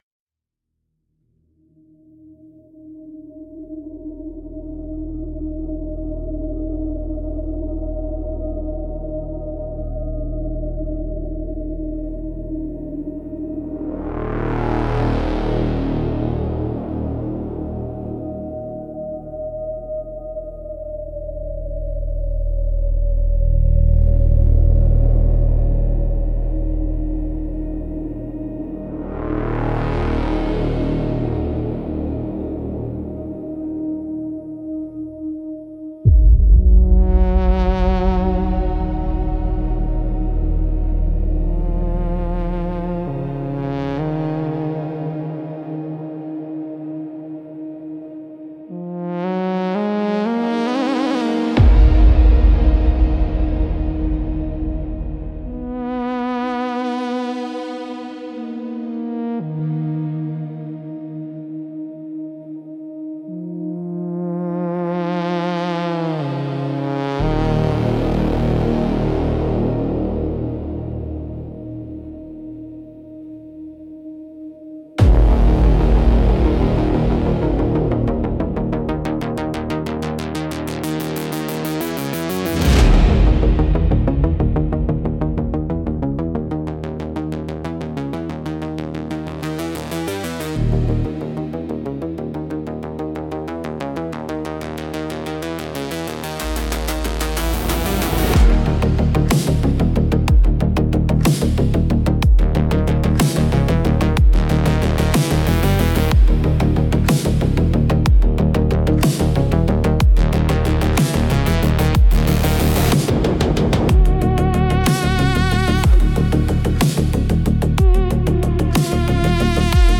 Instrumental - Static Lullaby for a Broken Drone 5.06